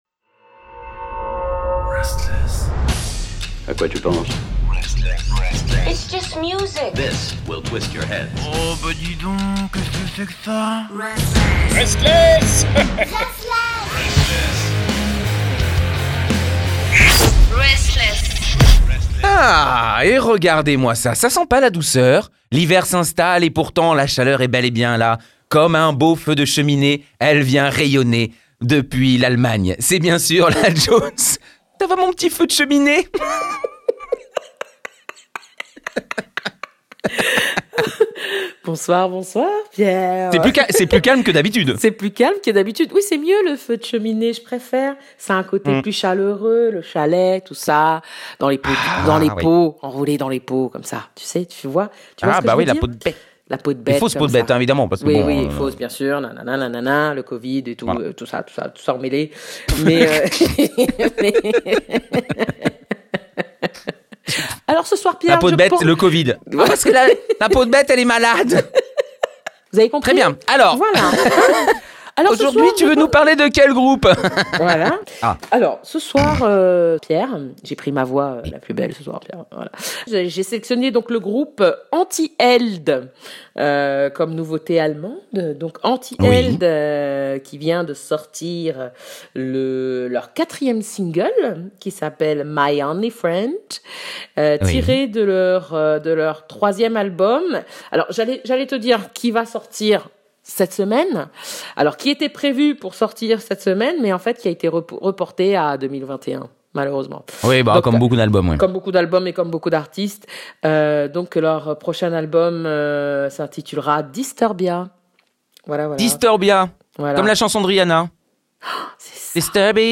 c’est tous les mercredis à 19h sur RSTLSS Radio.